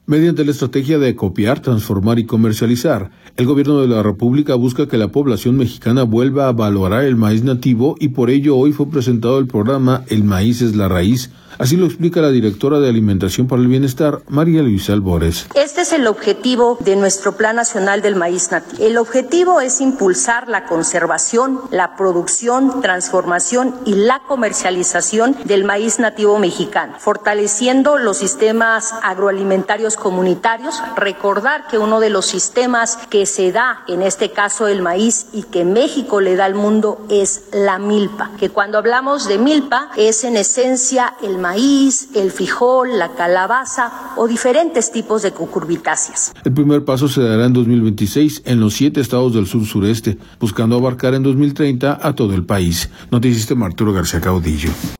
Mediante la estrategia de acopiar, transformar y comercializar, el Gobierno de la República busca que la población mexicana vuelva a valorar el maíz nativo y por ello hoy fue presentado el programa “El Maíz es la Raíz”, así lo explica la directora de Alimentación para el Bienestar, María Luisa Albores.